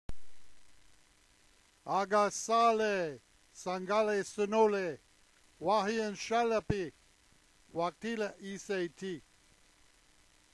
speaking the Yuchi greeting at the 4th Melungeon Union at Kingsport, Tennessee, June 2002
yuchigreeting.wma